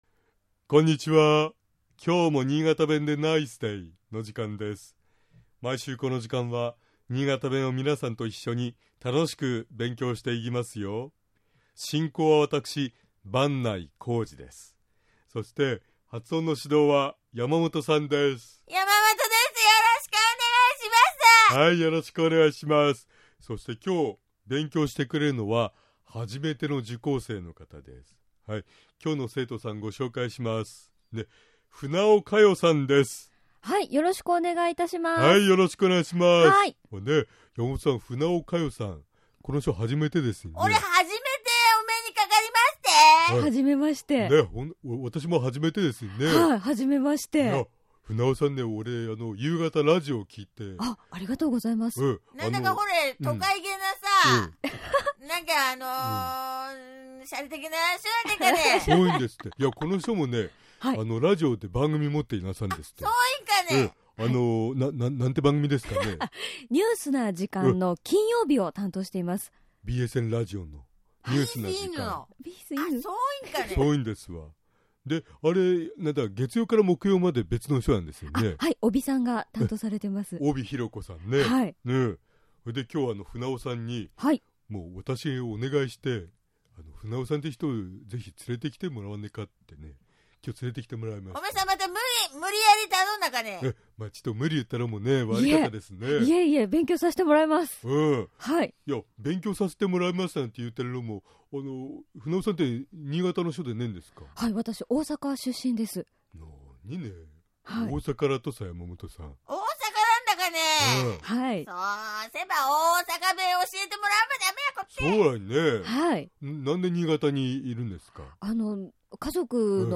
今日は笑いに関する言葉について勉強しましょう。 まず、「笑顔」と言う場合、新潟の人は「いがお」と発音します。